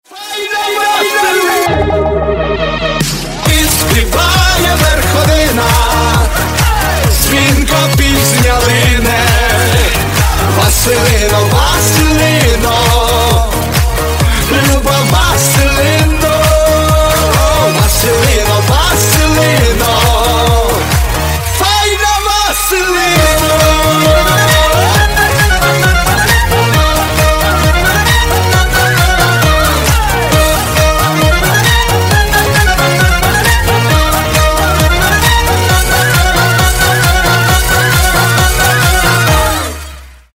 Громкие Рингтоны С Басами
Поп Рингтоны